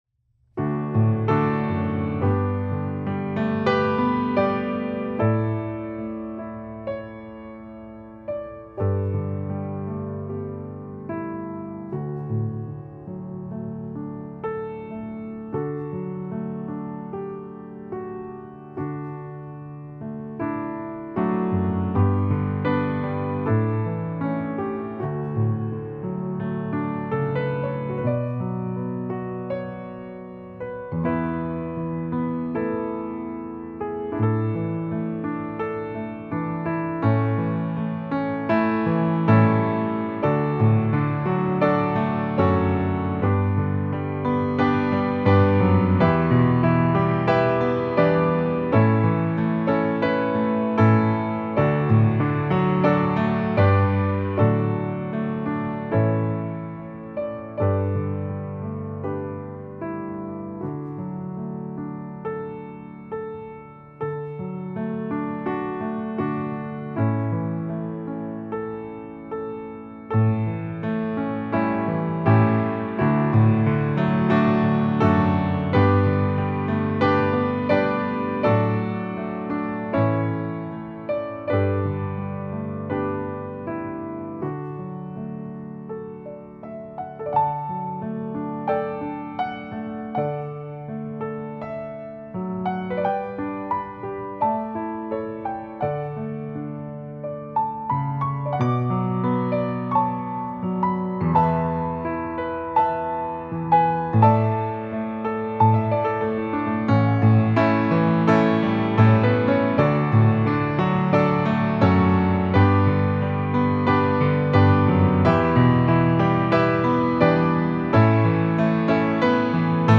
instrumental piano hymns